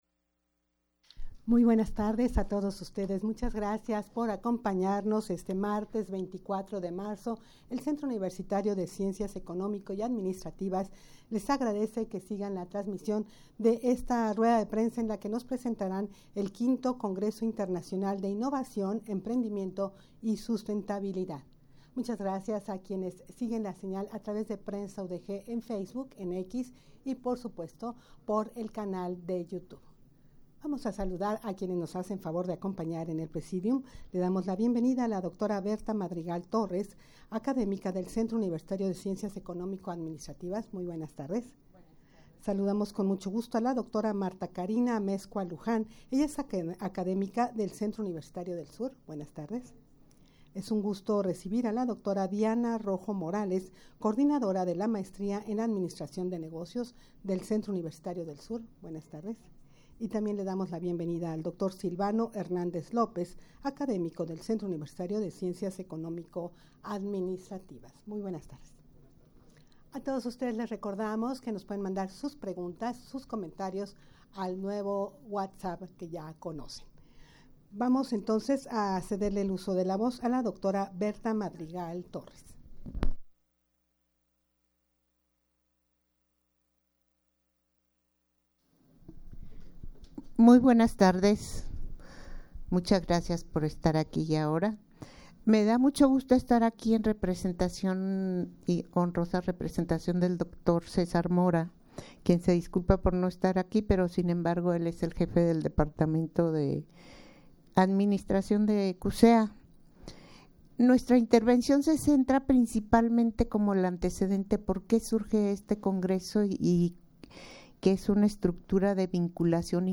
Audio de la Rueda de Prensa
rueda-de-prensa-presentacion-del-v-congreso-internacional-de-innovacion-emprendimiento-y-sustentabilidad.mp3